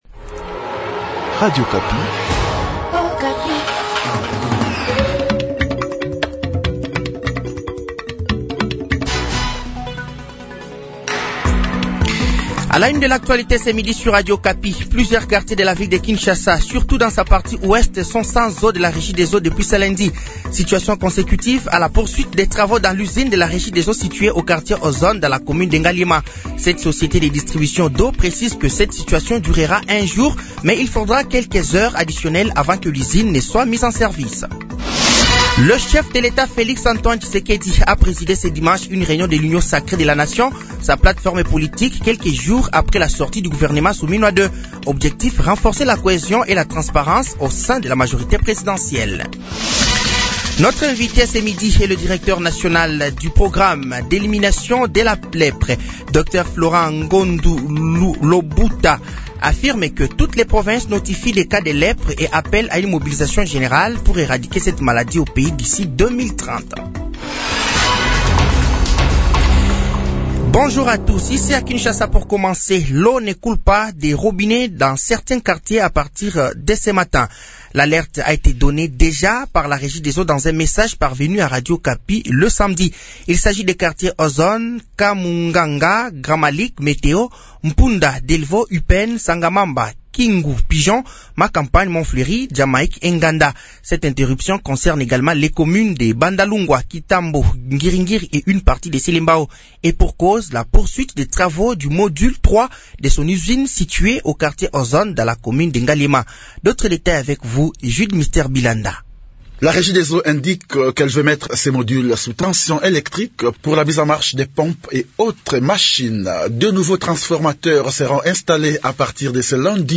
Journal français de 12h de ce lundi 11 août 2025